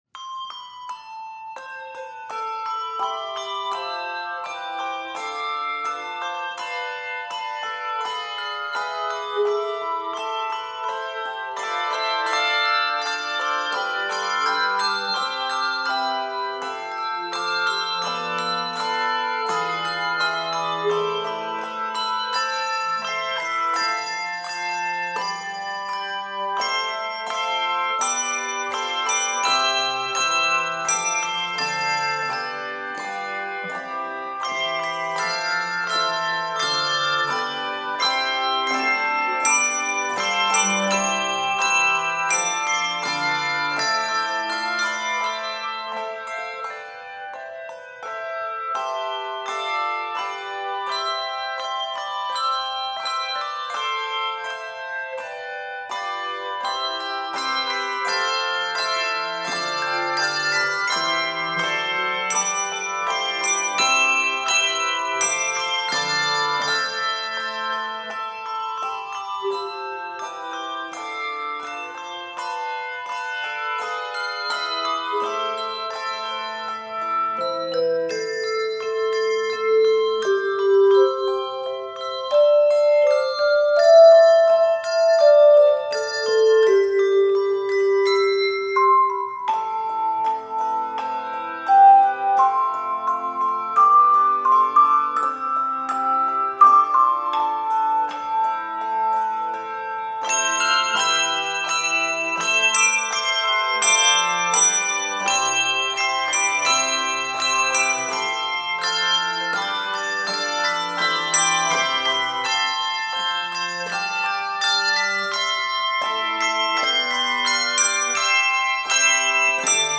Key of a minor.